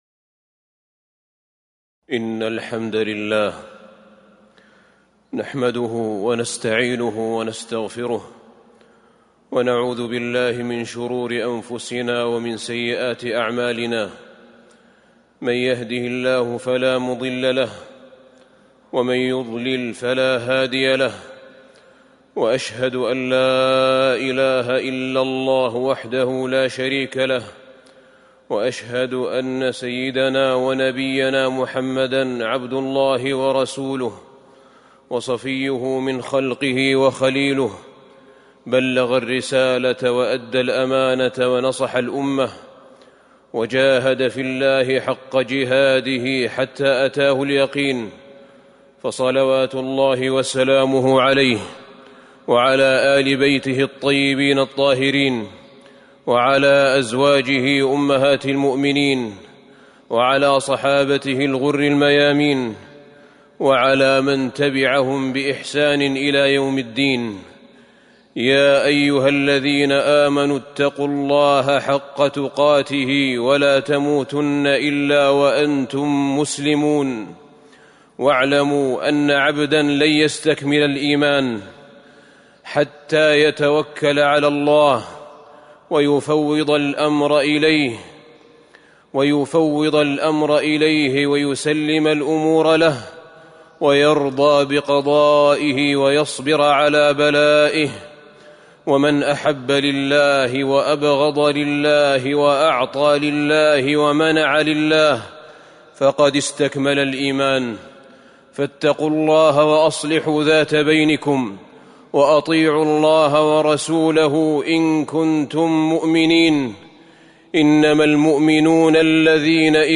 تاريخ النشر ٢٨ ربيع الثاني ١٤٤٣ هـ المكان: المسجد النبوي الشيخ: فضيلة الشيخ أحمد بن طالب بن حميد فضيلة الشيخ أحمد بن طالب بن حميد معالم الإيمان في سعي الدنيا والآخرة